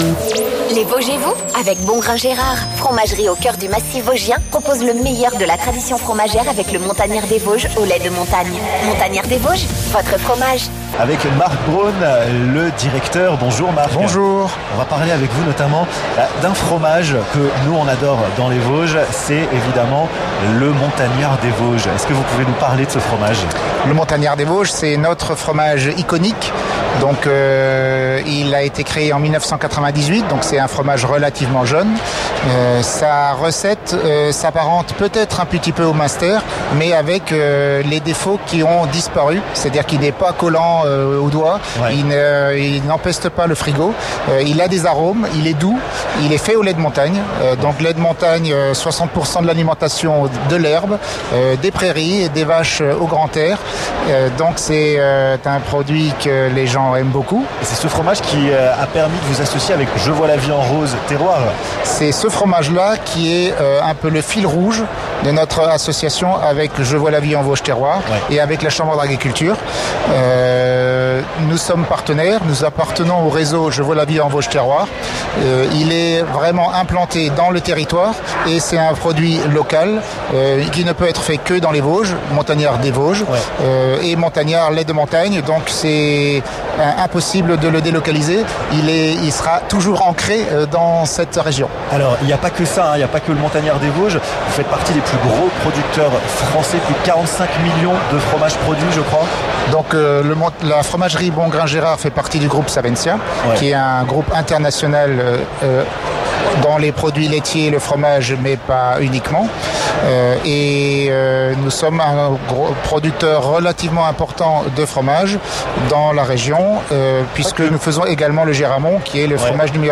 Nous vous proposons de revivre sa deuxième émission sur place avec la fromagerie Bongrain Gérard !